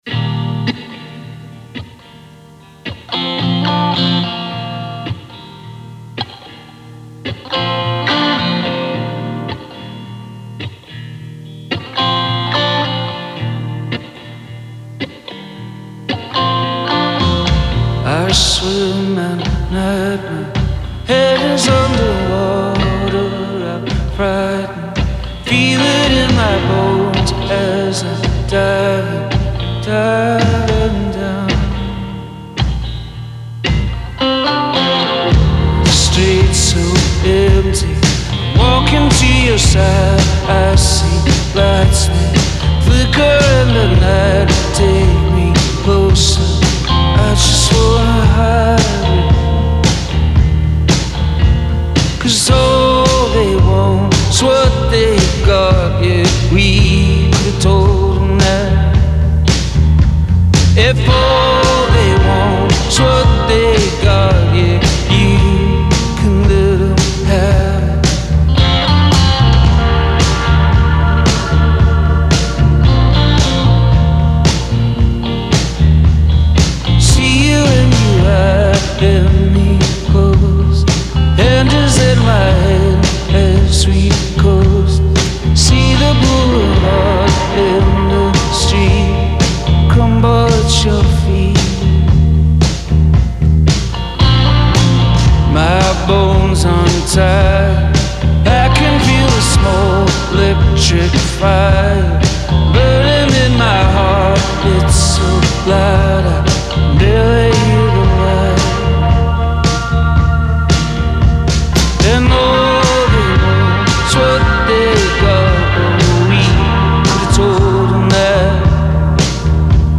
Genre : Rock